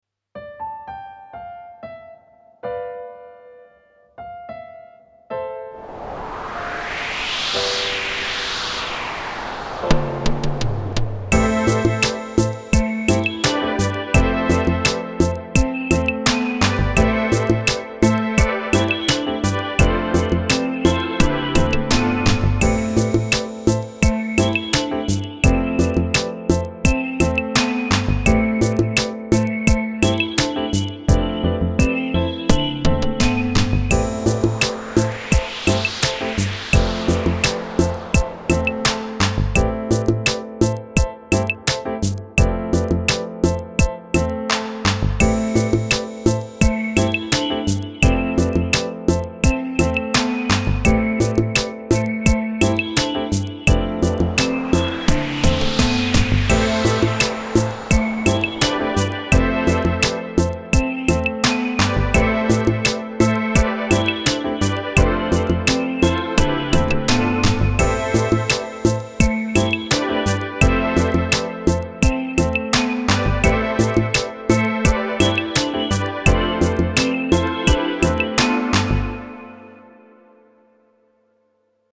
Zene:
weores_sandor_kis_versek_a_szelrol_instrumental.mp3